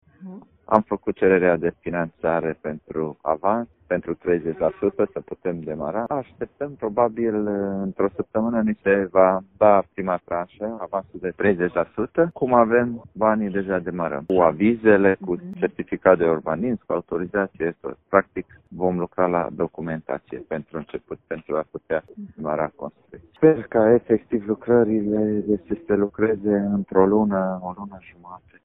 Primăria Târnăveni așteaptă deja prima tranșă de finanțare pentru demararea lucrărilor, a spus primarul Sorin Megheșan.